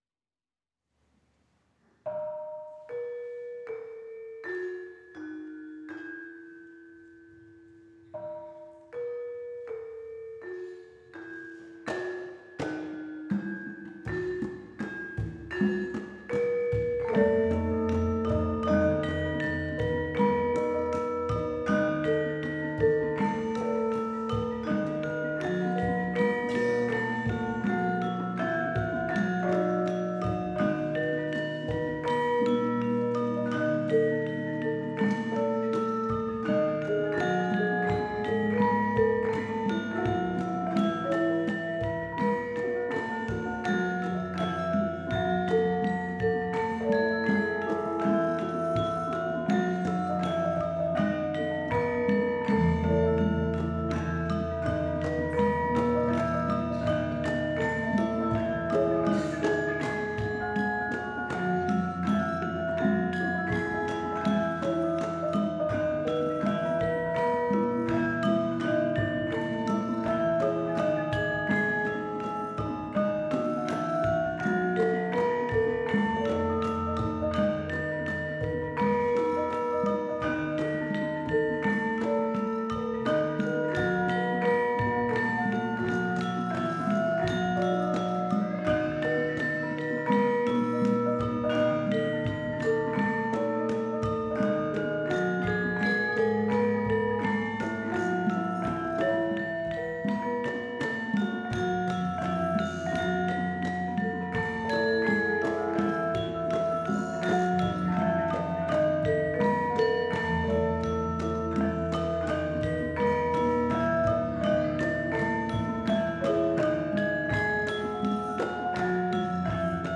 Double Concerto for Violin, Cello, and Javanese Gamelan, by Lou Harrison (1981)
violin
cello
First Movement in Pelog -- Ladrang Epikuros